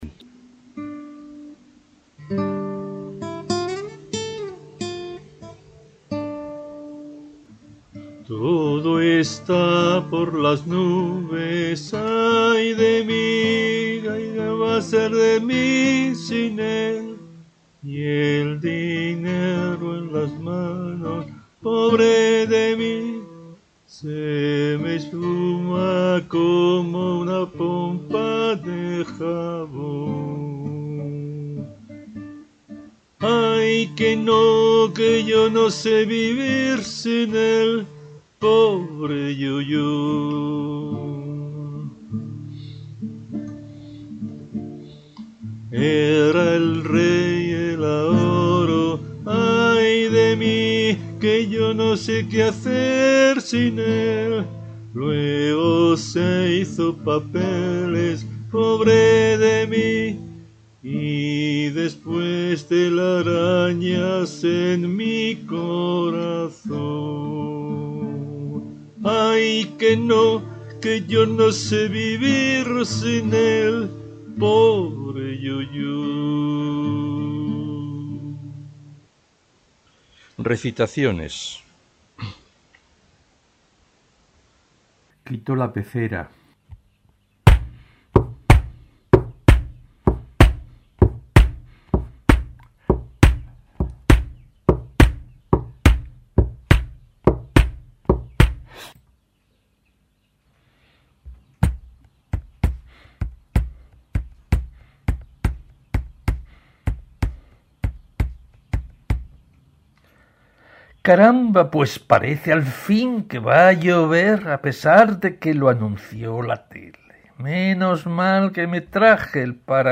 Grabamos a 44100 muestras por segundo, con sus valores de las muestras almacenados en 16 bits, en  estéreo.
Primero grabamos con un buen micrófono RØDE, en formato wav (PCM, sin pérdidas), y luego pasamos a mp3.
4.2 'Caramba, pues par.' 8/31 recita T6y.